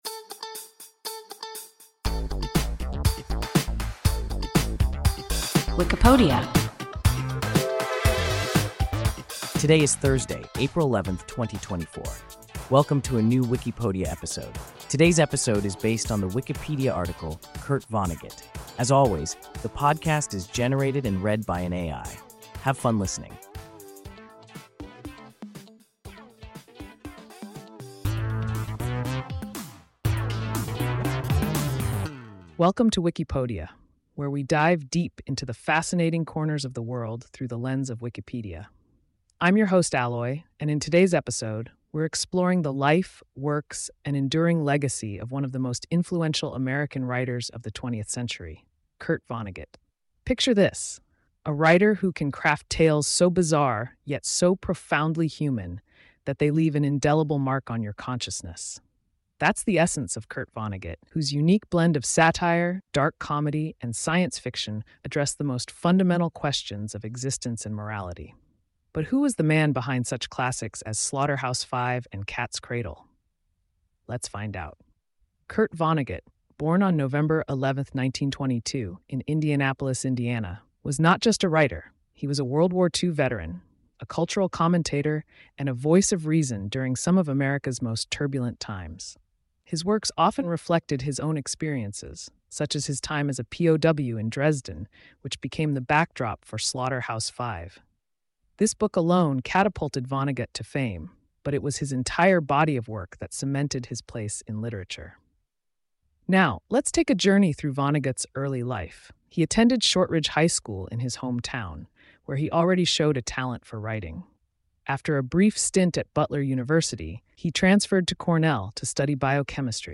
Kurt Vonnegut – WIKIPODIA – ein KI Podcast